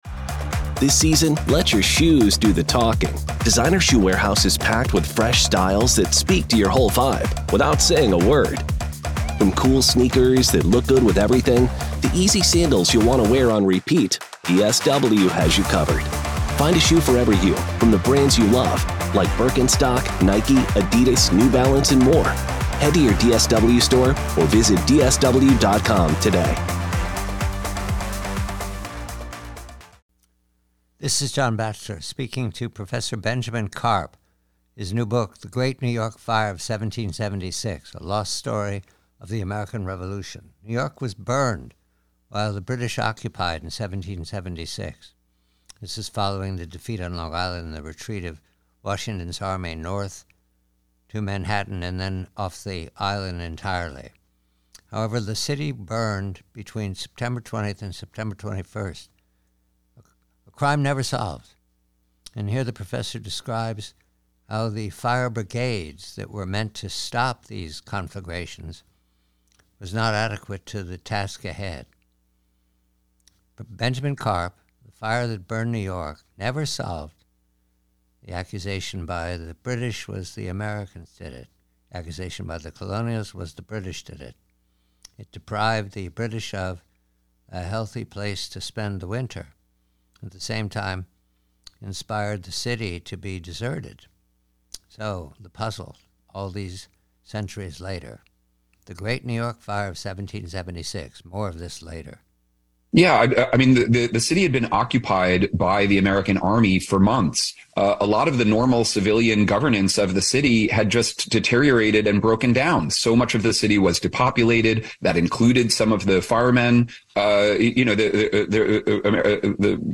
PREVIEW - NEW YORK CITY: 1776 Conversation